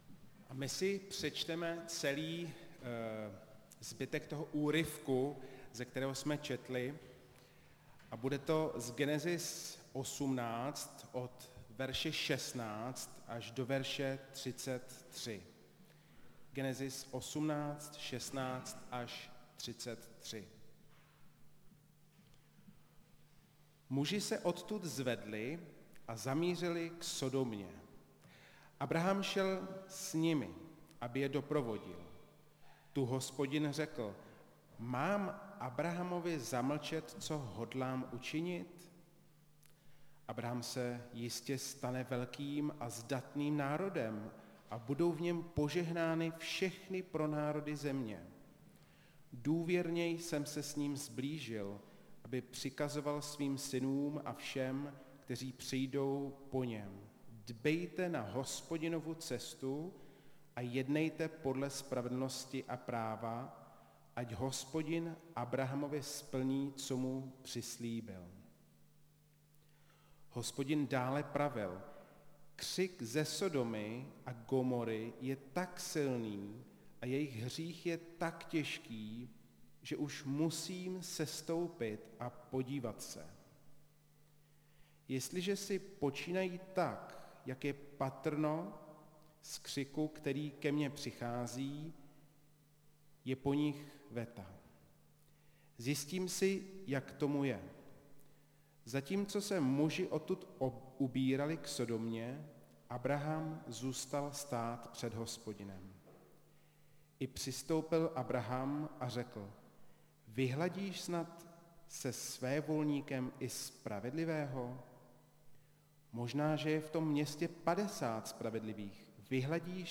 Nedělní kázání – 6.11.2022 Soud nad Sodomou